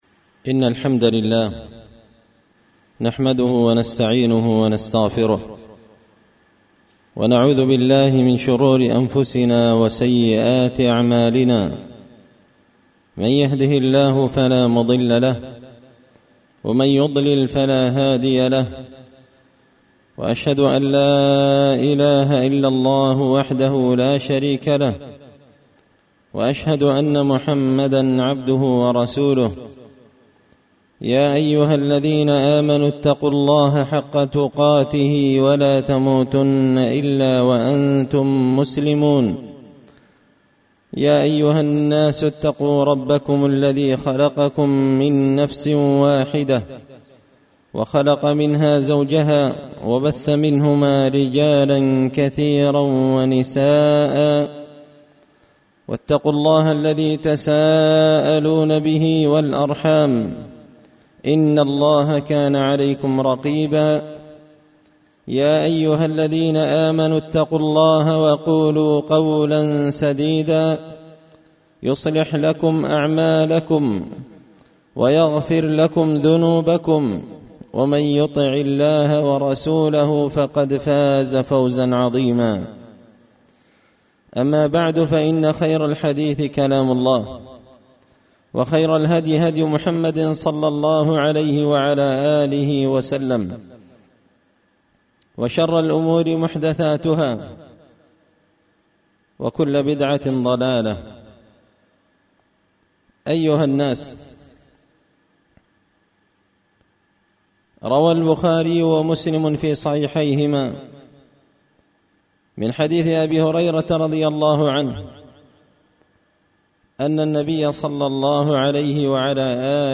خطبة جمعة بعنوان – فضل الصمت
دار الحديث بمسجد الفرقان ـ قشن ـ المهرة ـ اليمن